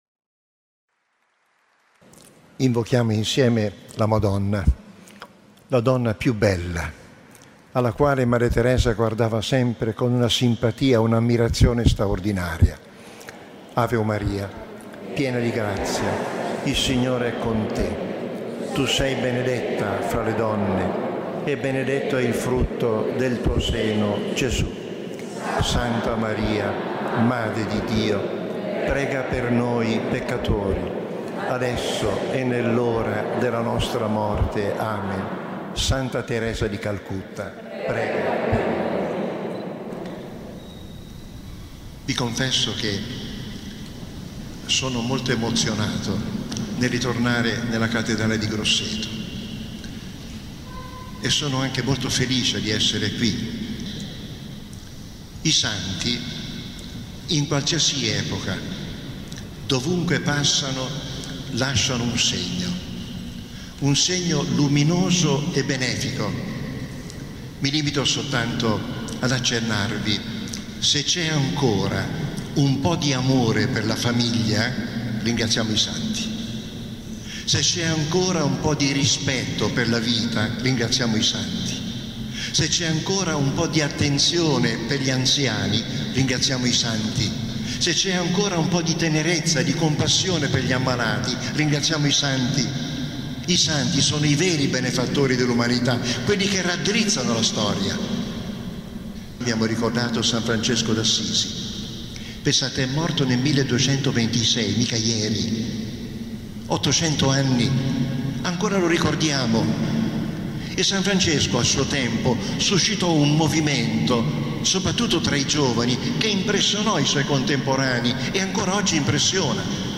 Cattedrale di Grosseto, Novembre 2018
Il card. Angelo Comastri, già arciprete della basilica di San Pietro e vicario generale del Papa per la Città del Vaticano, riflette sul carisma di Madre Teresa di Calcutta. L’incontro è stato organizzato il 24 novembre 2018 nella Cattedrale di Grosseto, in vista della dedicazione della nuova chiesa di Madre Teresa nel quartiere Cittadella, avvenuto il 2 ottobre successivo